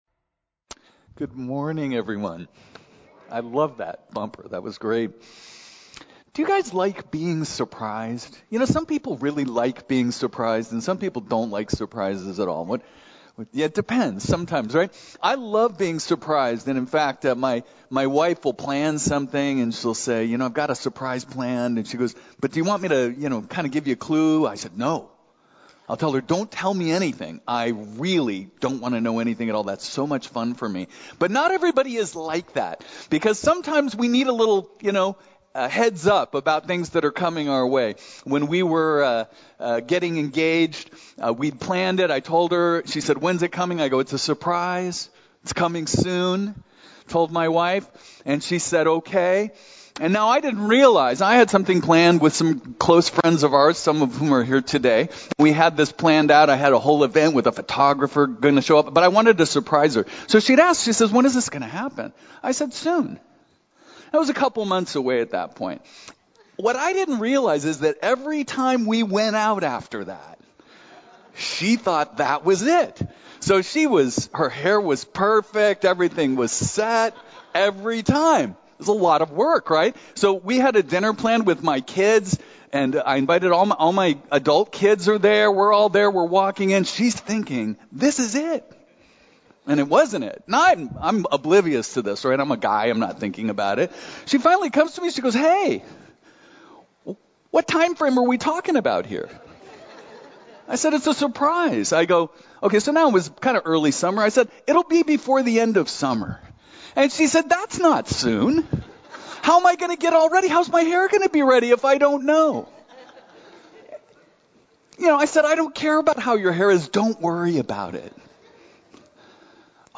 Sermon Notes: People followed Jesus for their own expectations and reasons. 1.